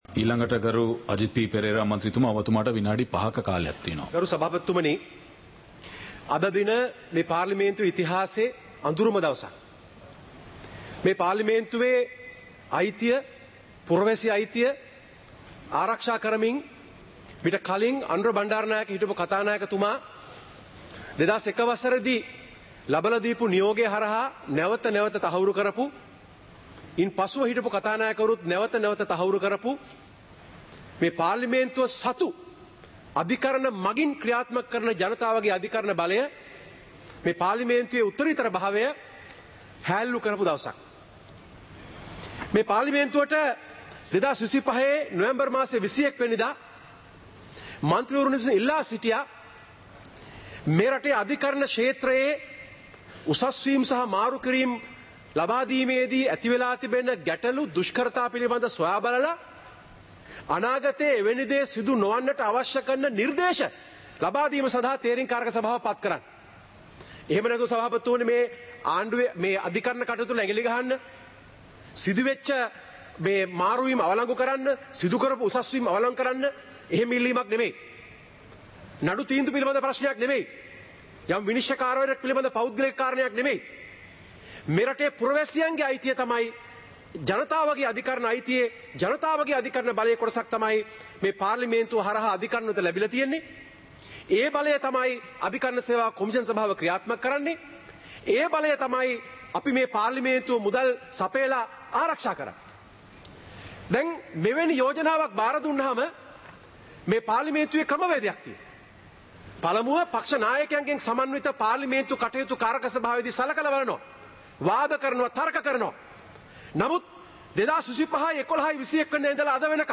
சபை நடவடிக்கைமுறை (2026-01-09)
நேரலை - பதிவுருத்தப்பட்ட